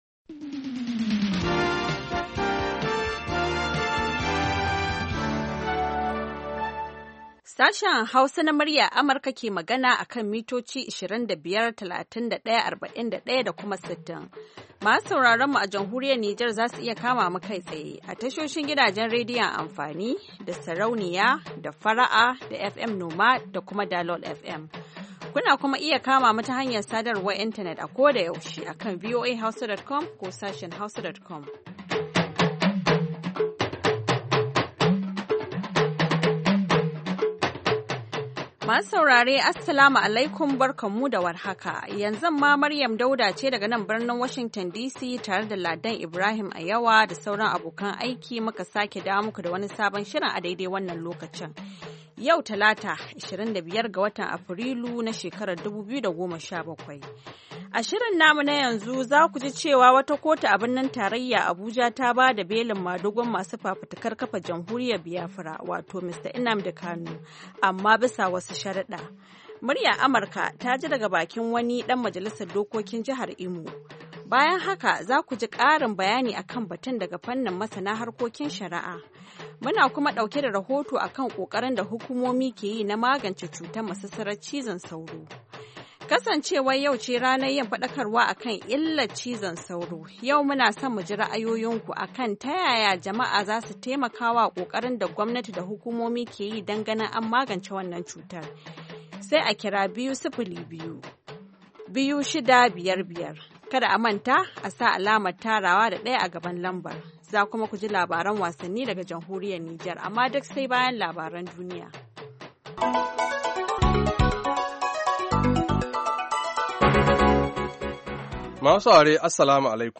A shirin namu na karfe 9 da rabi agogon Najeriya da Nijar, zaku ji labarai na yadda duniyar ta yini da rahotanni da dumi-duminsu, sannan mu kan bude muku layuka domin ku bugo ku bayyana mana ra’ayoyinku kan batutuwan da suka fi muhimmanci a wannan rana, ko kuma wadanda ke ci muku tuwo a kwarya.